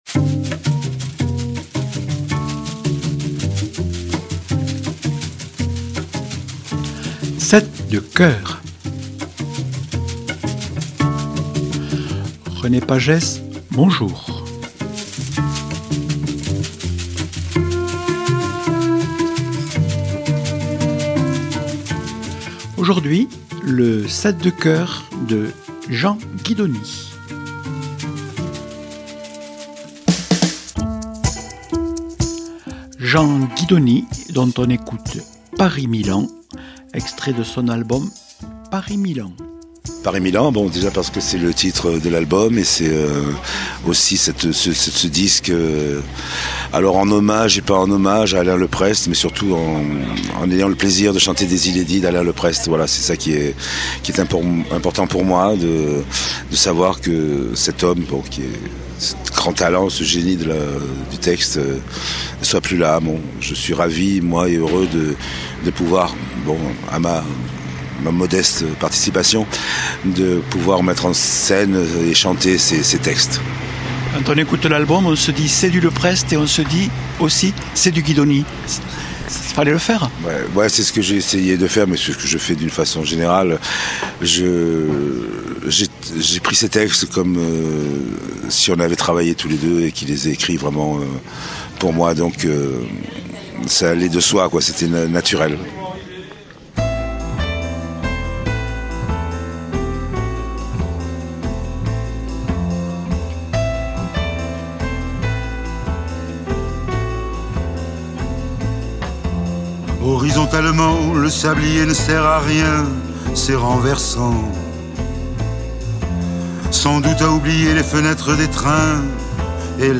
Invité(s) : Jean Guidoni, chanteur et parolier.